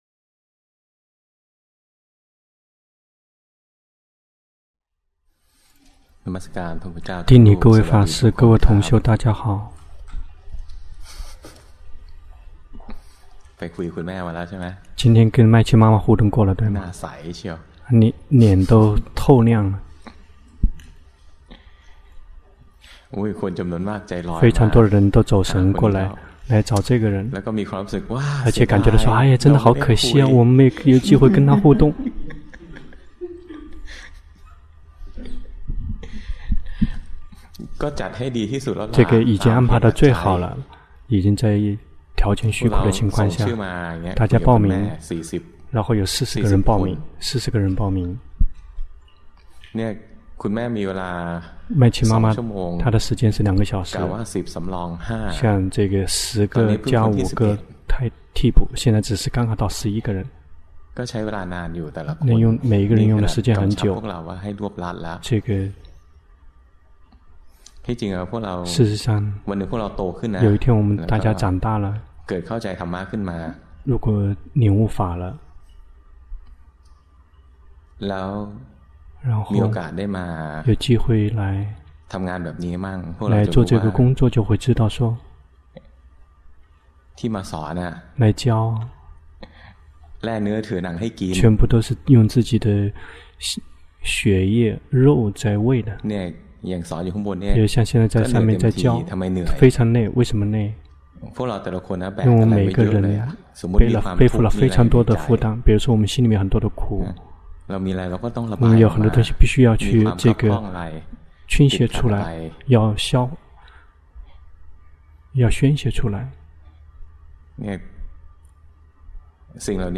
第十二屆泰國四念處禪修課程 同聲翻譯